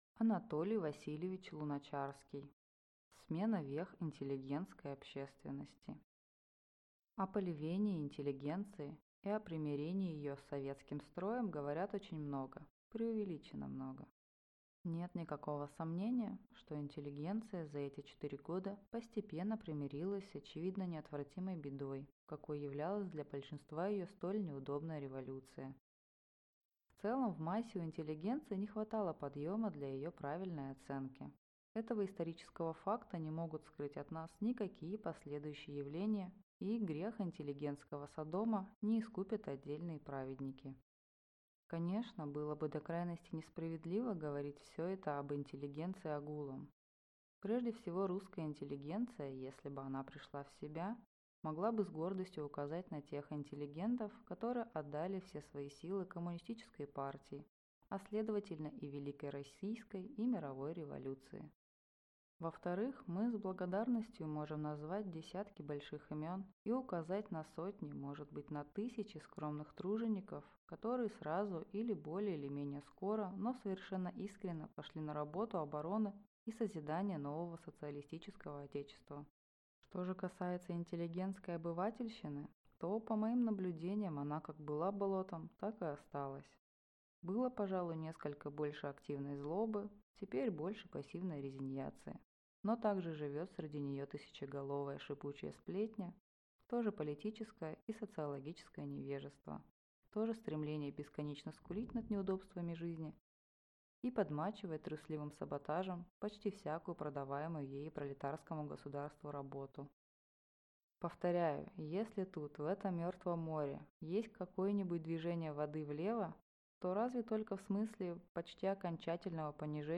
Аудиокнига Смена вех интеллигентской общественности | Библиотека аудиокниг